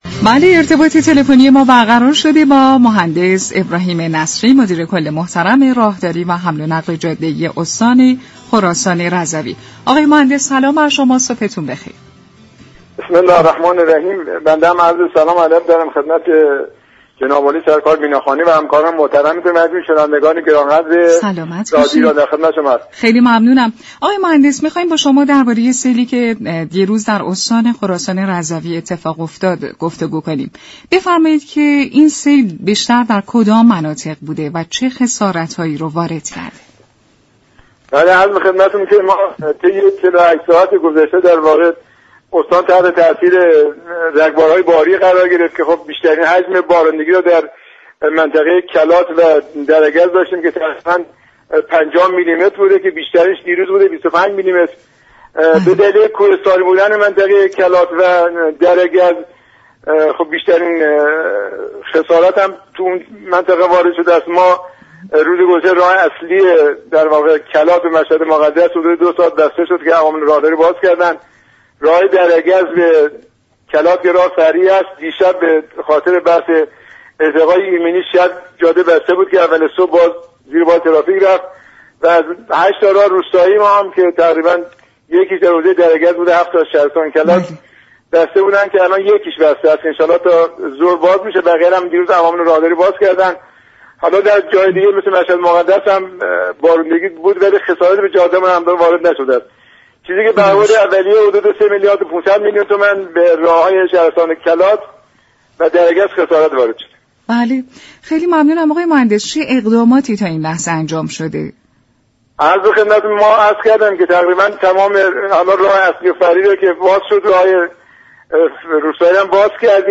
مهندس ابراهیم نصری مدیر كل راهداری و حمل و نقل جاده ای استان خراسان رضوی در گفت و گو با برنامه صدای راهبران به سیل در این استان اشاره كرد و گفت: بارش های بهاری همراه با رگبار در استان خراسان رضوی مناطق كلات و درگز را تحت تاثیر خود قرار داد و این بخش ها را با خسارات زیاد مواجه كرد.